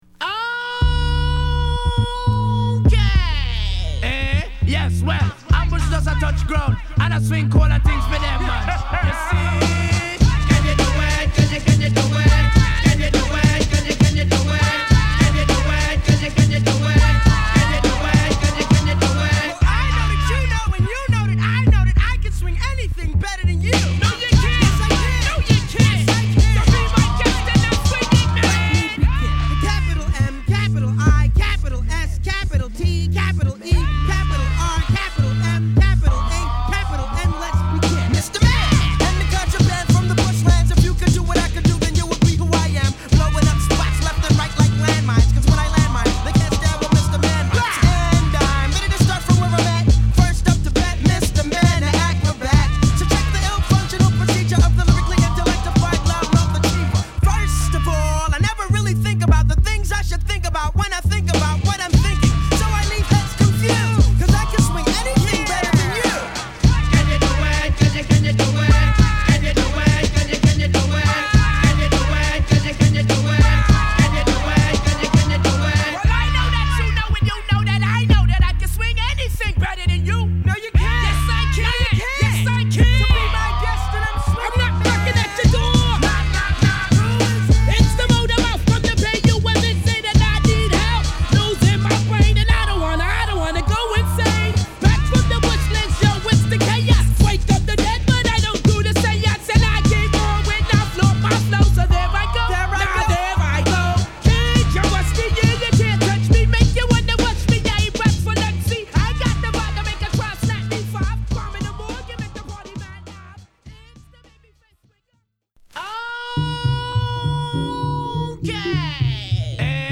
タイトル通り疾走感あるスウィンギーなトラックでテンション高いラップ／コーラスが乗る名作！